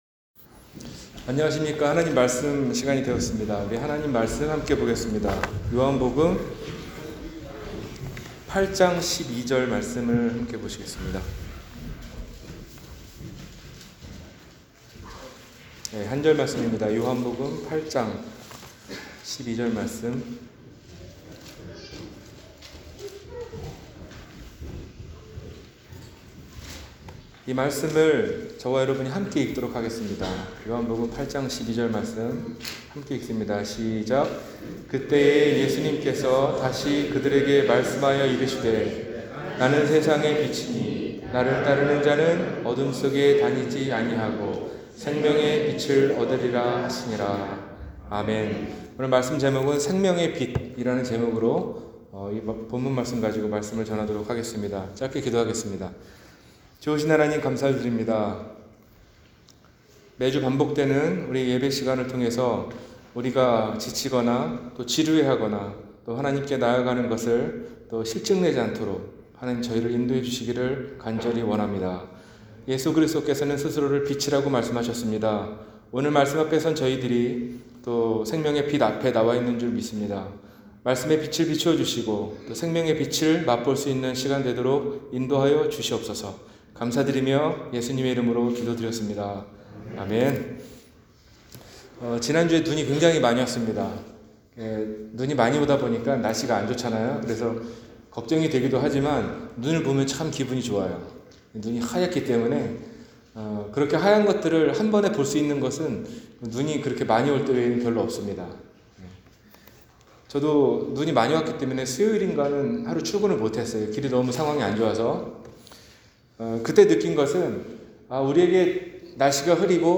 생명의 빛 – 주일설교